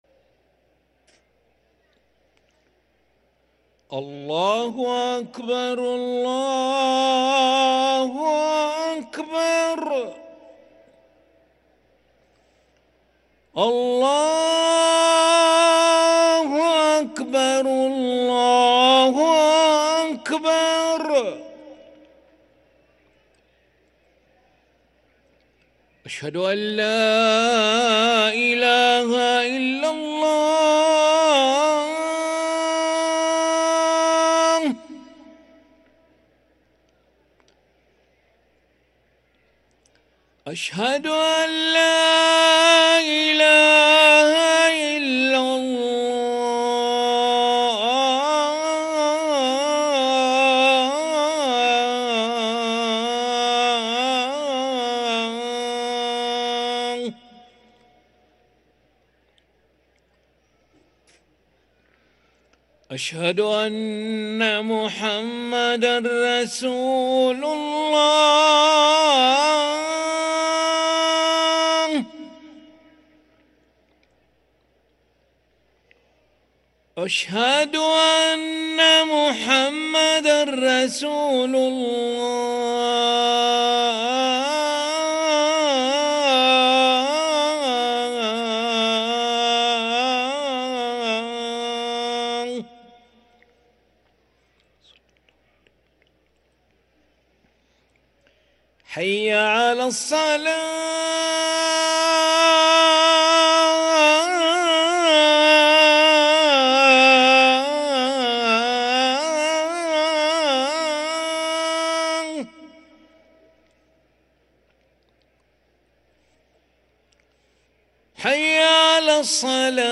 أذان العشاء للمؤذن علي أحمد ملا الأحد 17 شوال 1444هـ > ١٤٤٤ 🕋 > ركن الأذان 🕋 > المزيد - تلاوات الحرمين